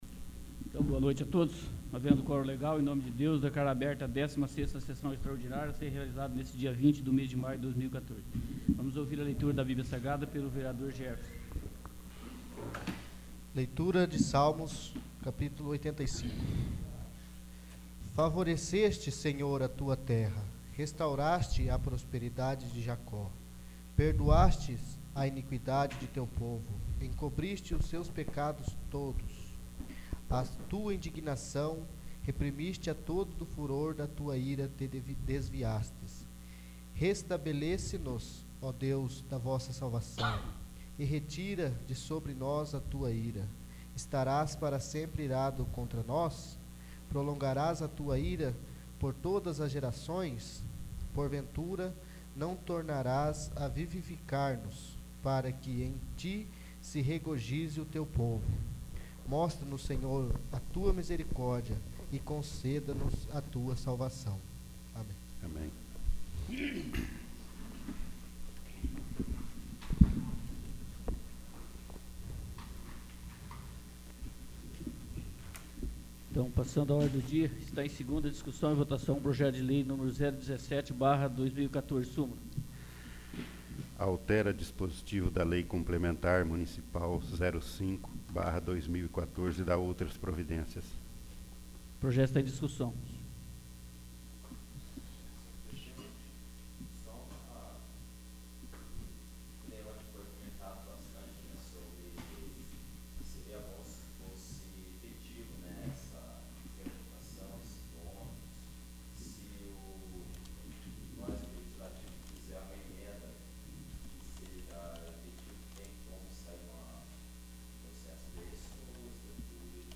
16º. Sessão Extraordinária